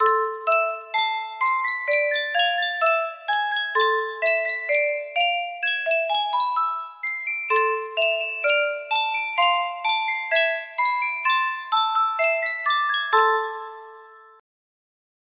Yunsheng Music Box - Old King Cole 5155 music box melody
Full range 60